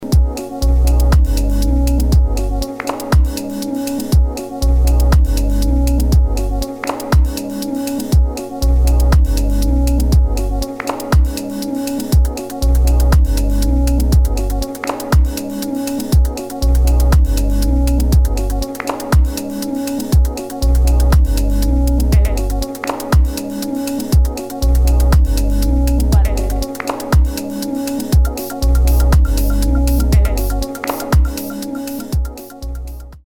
[ DOWNBEAT / BASS / EXPERIMENTAL ]